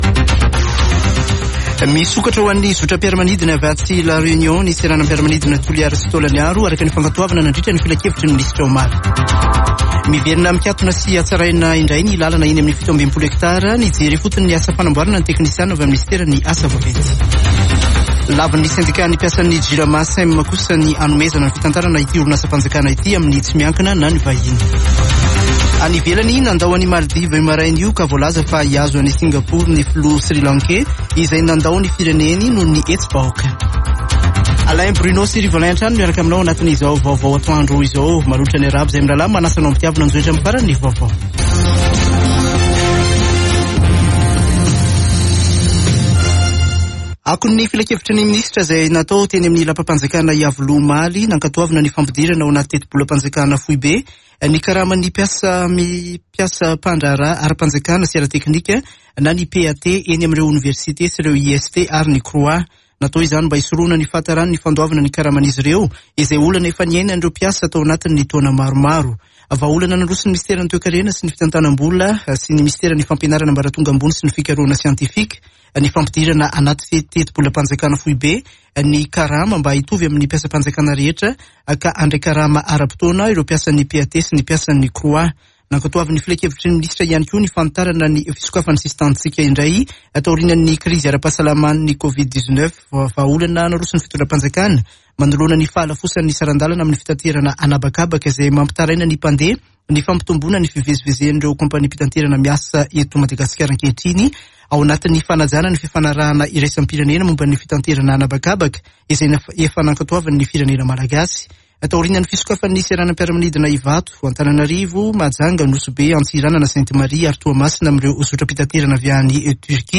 [Vaovao antoandro] Alakamisy 14 jolay 2022